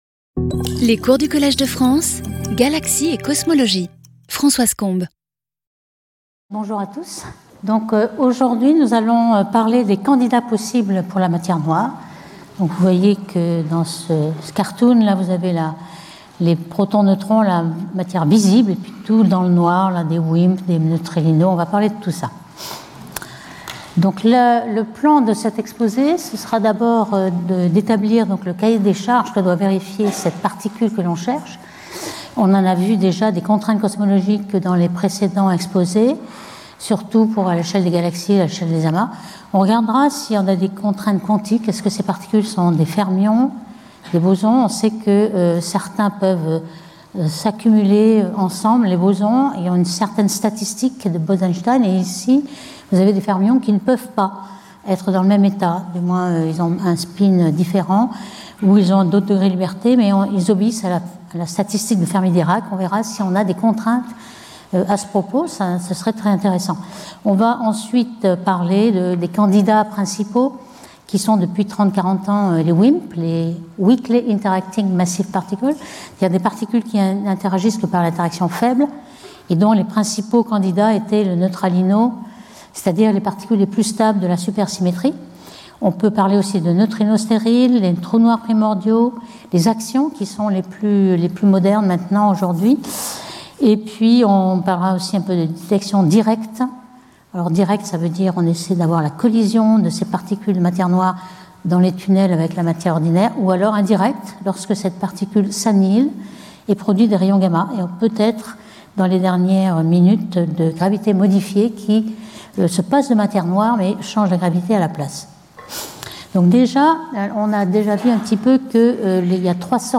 Intervenant(s) Françoise Combes Professeure du Collège de France
Cours